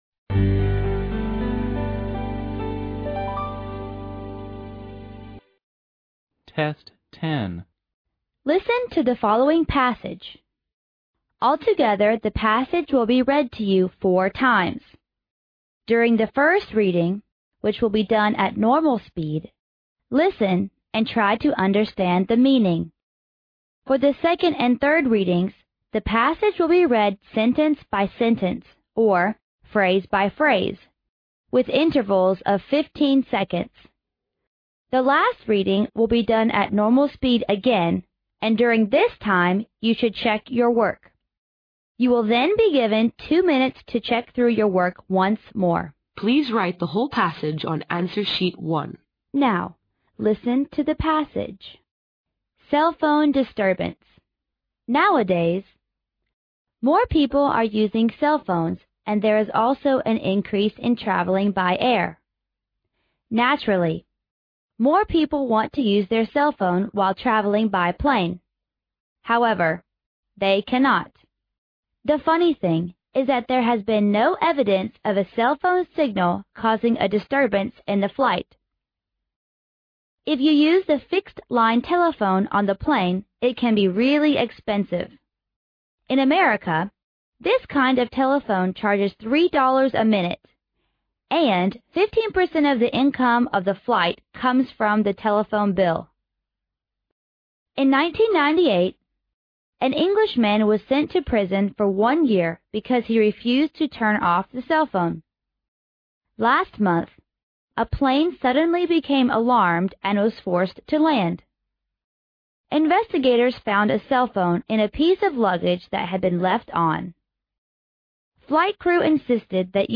Altogether the passage will be read to you four times.
The last reading will be done at normal speed again and during this time you should check your work.